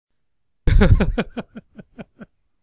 Laugh.ogg